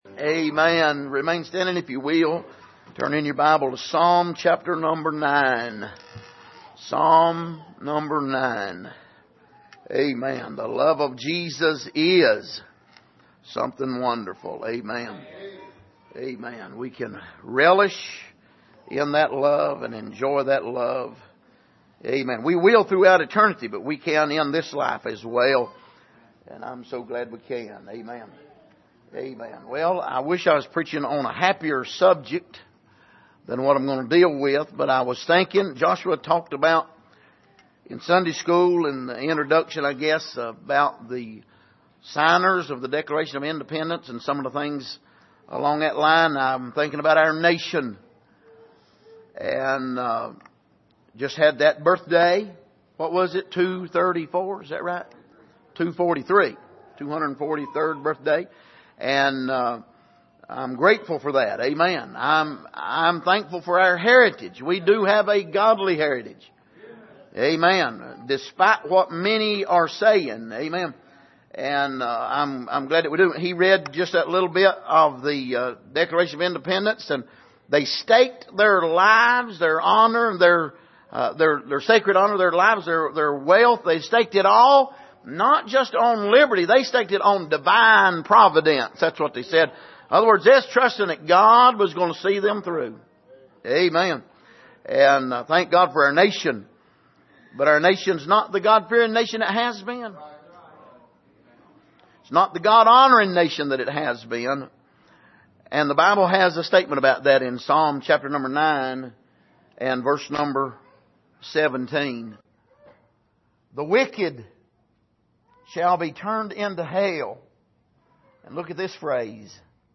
Passage: Psalm 9:17 Service: Sunday Morning